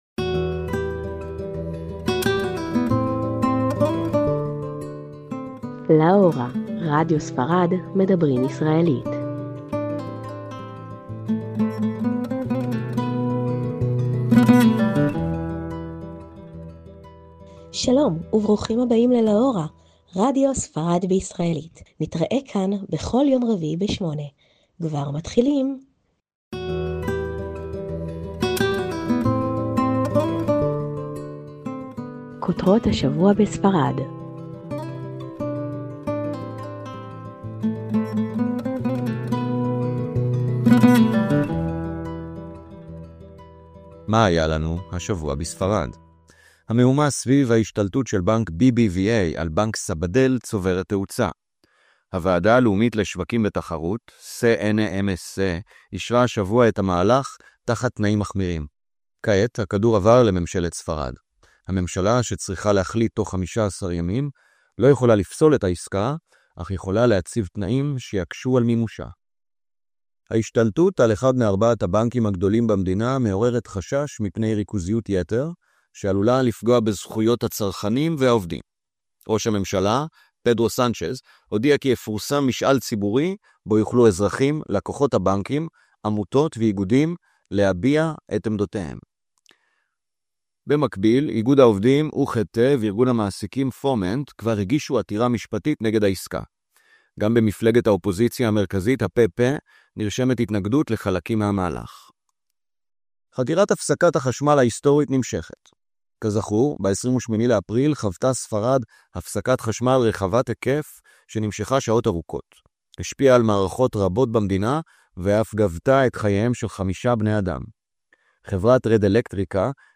תכנית רדיו בעברית לטובת הישראלים בספרד